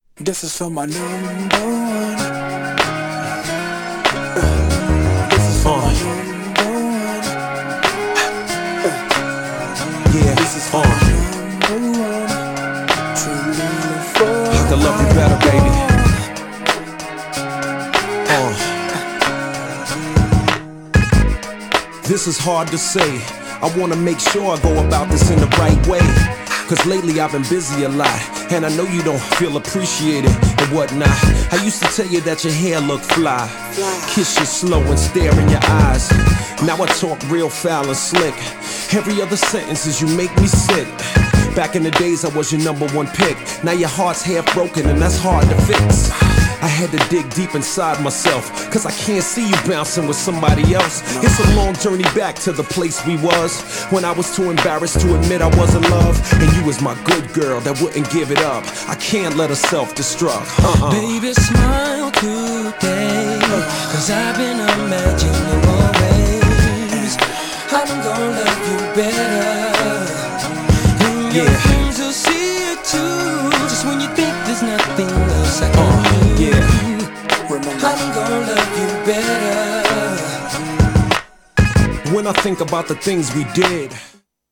GENRE Hip Hop
BPM 96〜100BPM